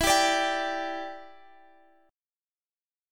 G5/E chord